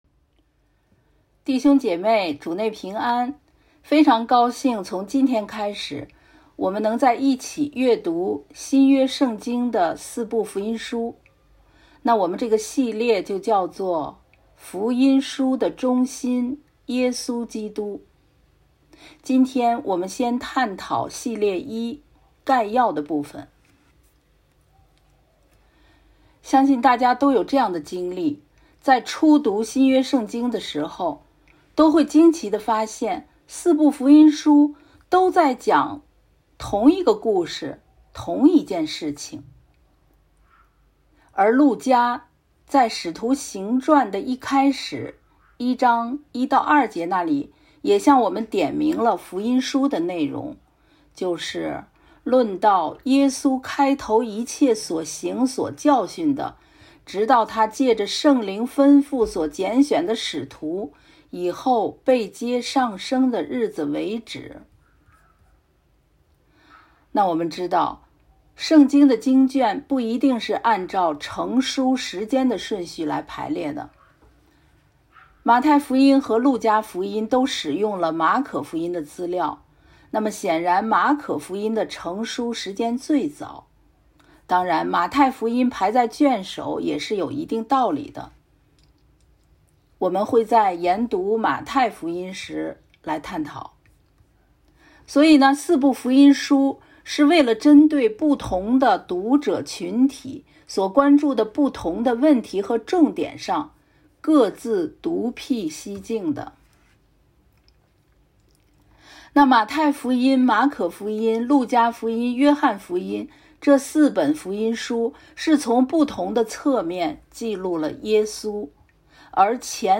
《福音书的中心—耶稣基督》 | 北京基督教会海淀堂